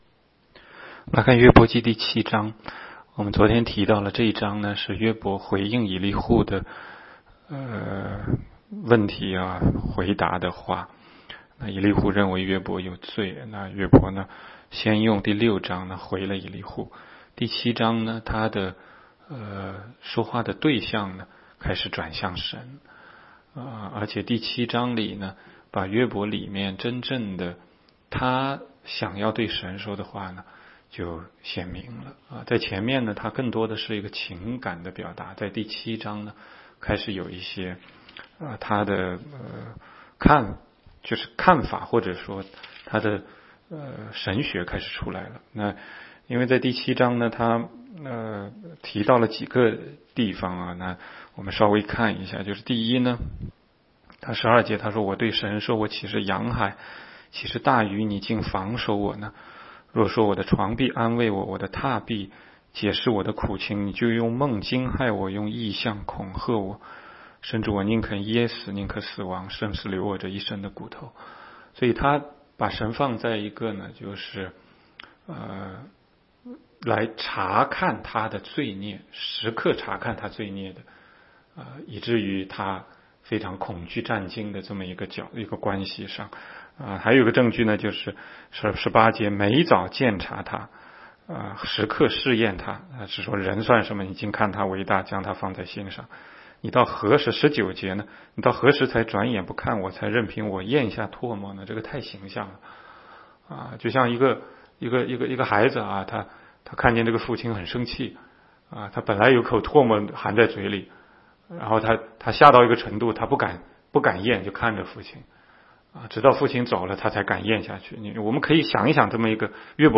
16街讲道录音 - 每日读经-《约伯记》7章